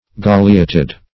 Search Result for " galeated" : The Collaborative International Dictionary of English v.0.48: Galeate \Ga"le*ate\, Galeated \Ga"le*a`ted\, a. [L. galeatus, p. p. of galeare helmet.] 1.
galeated.mp3